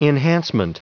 Prononciation du mot enhancement en anglais (fichier audio)
Prononciation du mot : enhancement